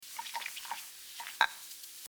Washing Dishes
Washing_dishes.mp3